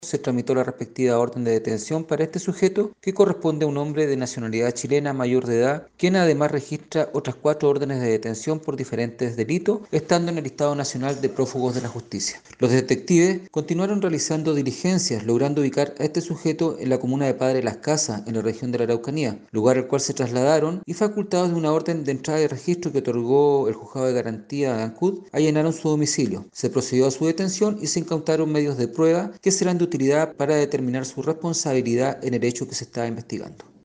El oficial de la Policía de Investigaciones añadió que, luego de diversas indagatorias, se logró establecer la ubicación del individuo en la región de La Araucanía, hasta donde había escapado tras protagonizar el robo en Ancud.